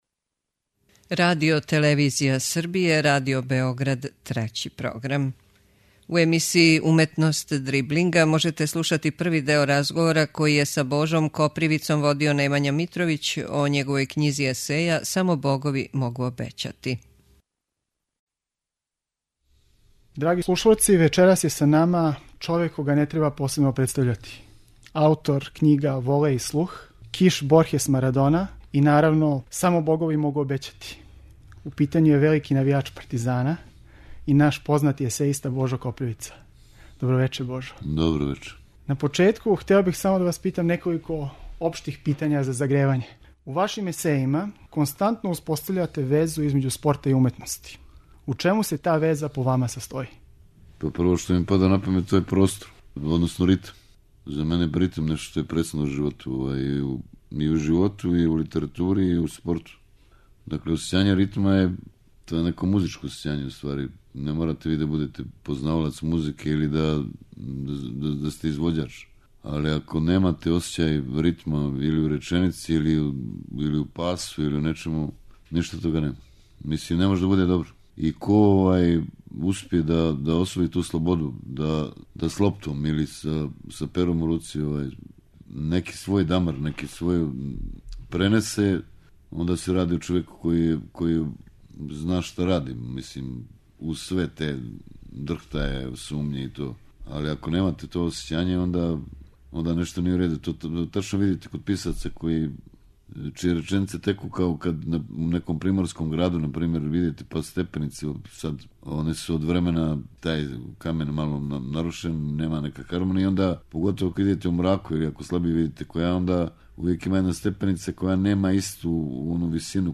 можете слушати први део разговора